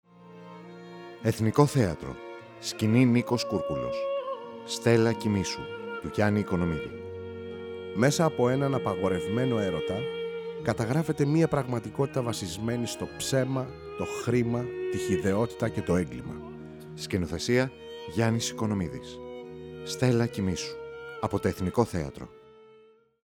STELLA-KOIMHSOU_MIX_mixdown-with-music.mp3